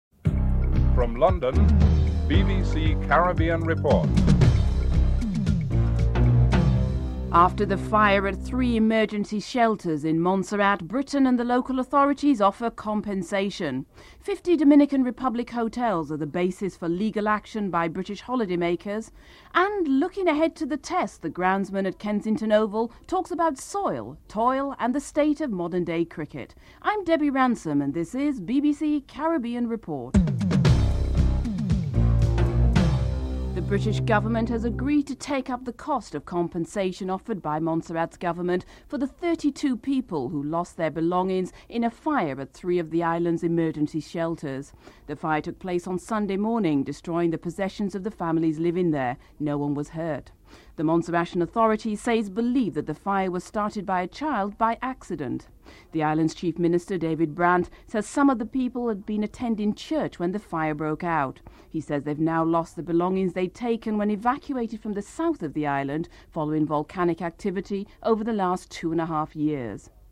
2. After a fire at three emergency shelters in Montserrat, the British government has agreed to cover the cost of compensation. Chief Minister David Brandt comments on the issue (00:33-02:22)
9. Recap of top stories (14:25-15:05)